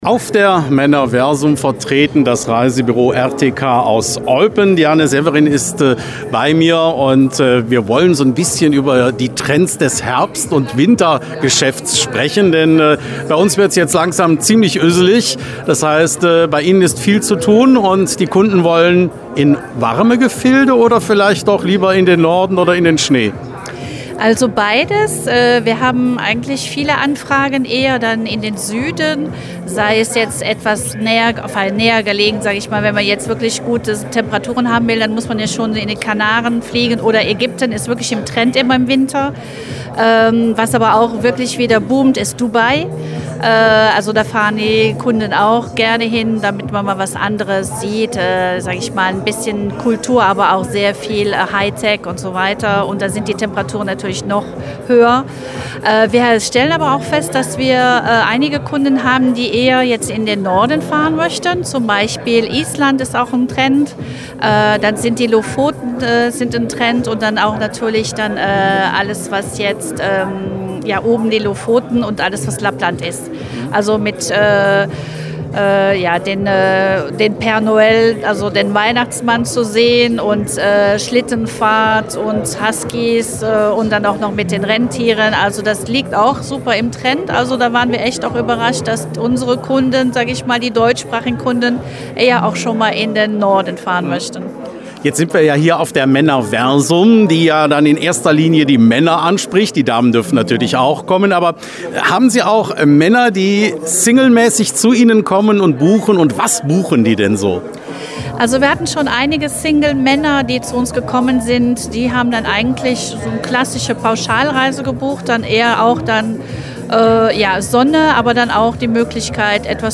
Am Wochenende (3. – 5. Oktober 2025) fand in Eupen in der Eastbelgica Eventlocation die Erlebnissemesse „Männerversum“ statt. Mit dabei auch das Reisebüro RTK.